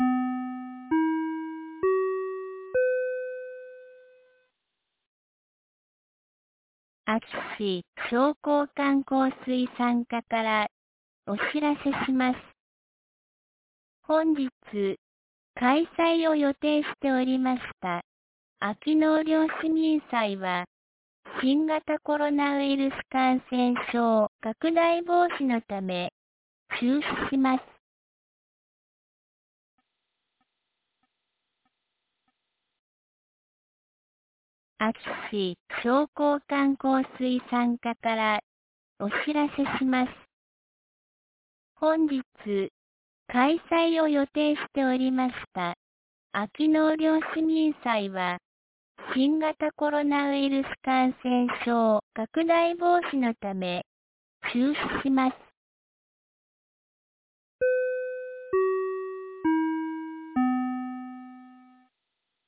2022年08月07日 12時06分に、安芸市より全地区へ放送がありました。